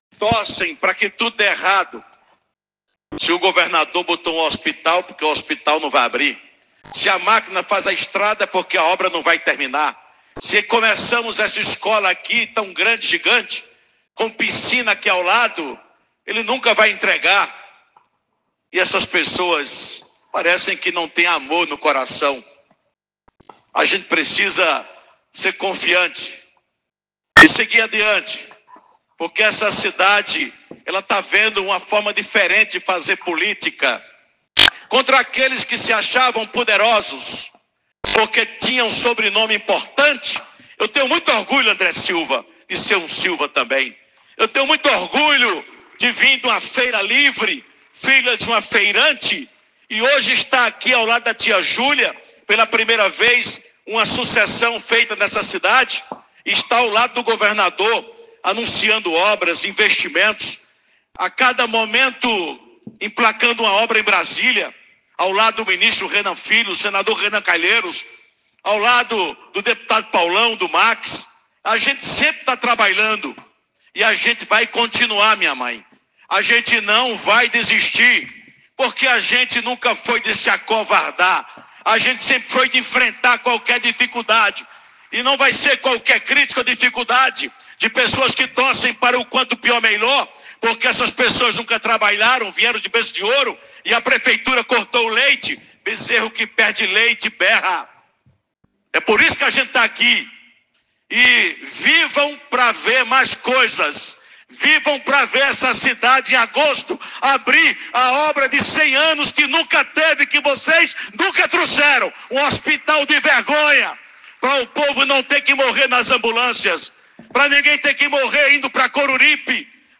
Áudio: o recado de Júlio Cezar aos opositores durante visita do governador